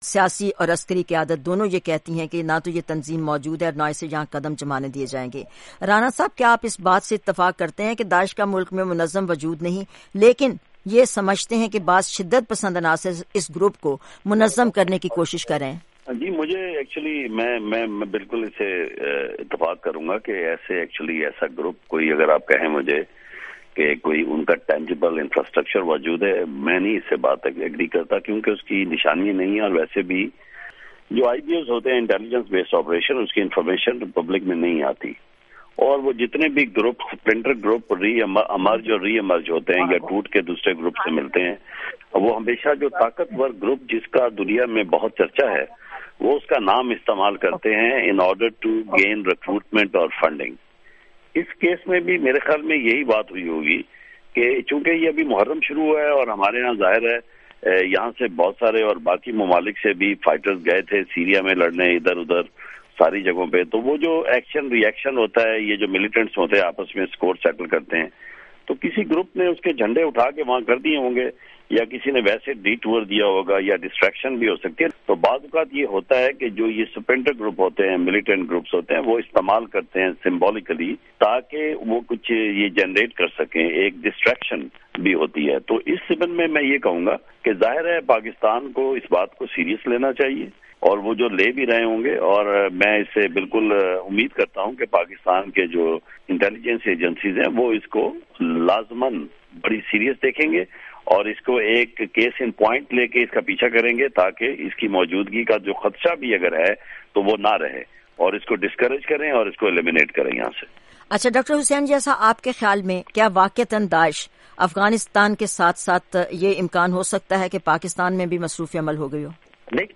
پاکستان۔ افغانستان اور امریکہ سے اپنے تجزیہ کاروں کی رائے معلوم کی۔